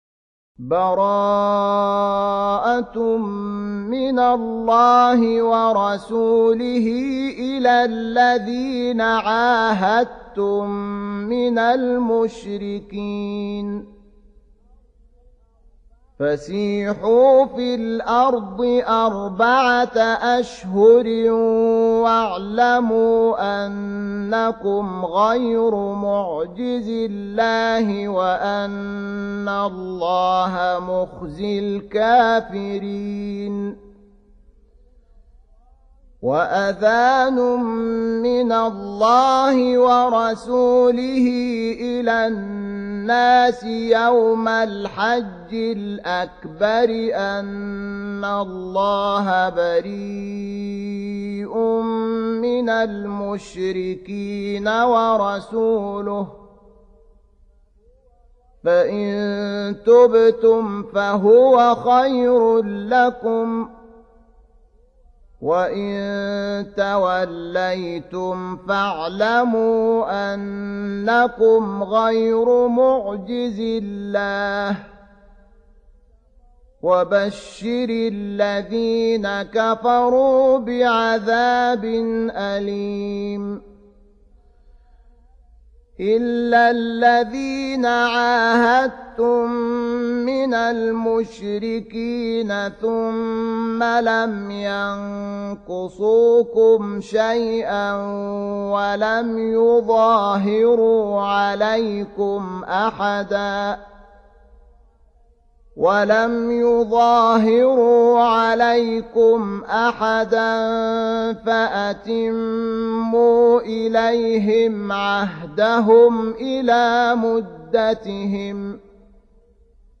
Surah Repeating تكرار السورة Download Surah حمّل السورة Reciting Murattalah Audio for 9. Surah At-Taubah سورة التوبة N.B *Surah Excludes Al-Basmalah Reciters Sequents تتابع التلاوات Reciters Repeats تكرار التلاوات